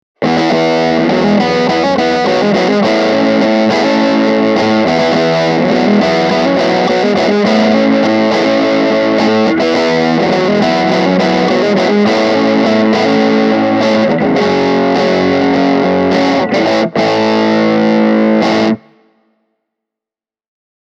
JTM:n särösoundit ovat miedompia ja lämpimämpiä kuin JMP:n. JMP1C on säröllä runsaasti tuoreempi ja rapeampi ja se kuulostaa enemmän vahvistinstäkiltä kuin JTM, jonka sointi on hyvin Bluesbreaker-tyylinen:
Marshall JMP1C – Les Paul Junior/särö